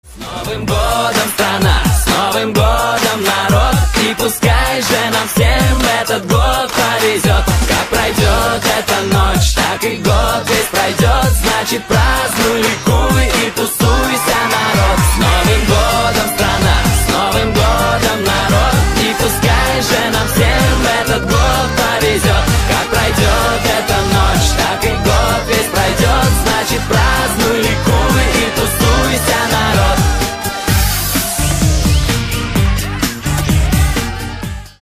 поп
позитивные
мужской вокал
громкие
веселые
вдохновляющие
танцевальные